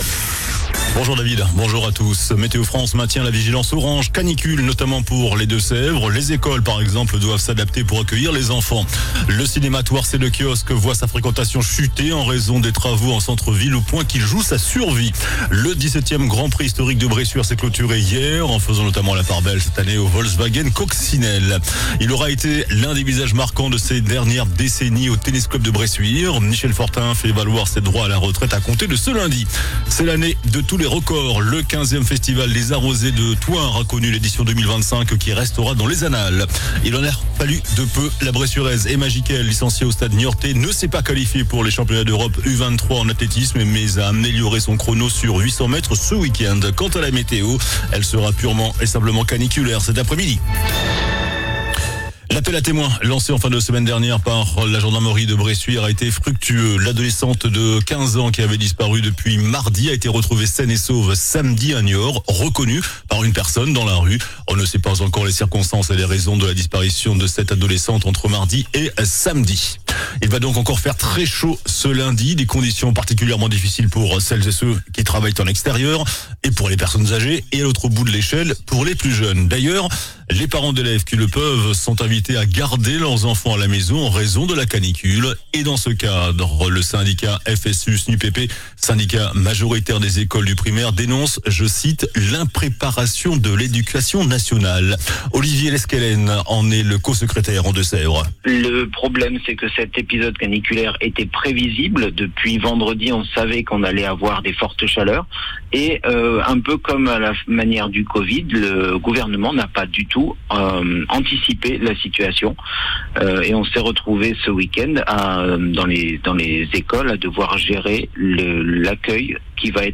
JOURNAL DU LUNDI 30 JUIN ( MIDI )